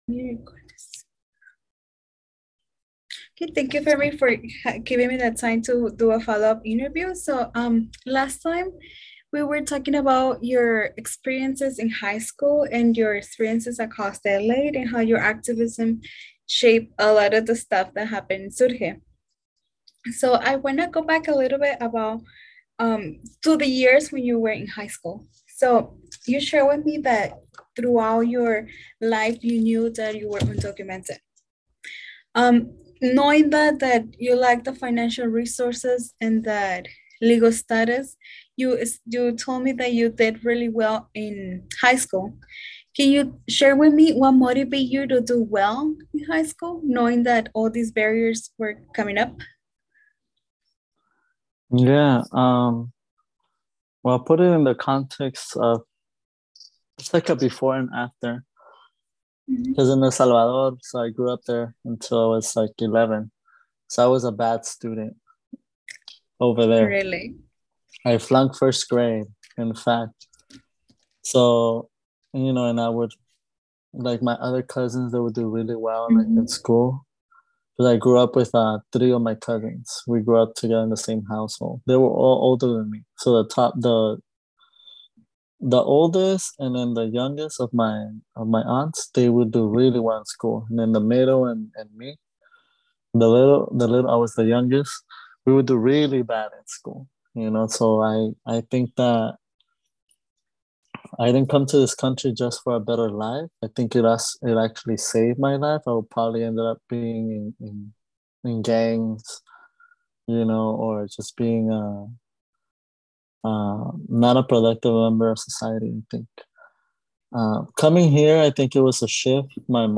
This is the second third of the interview.